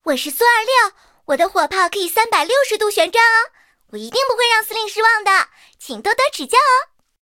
SU-26登场语音.OGG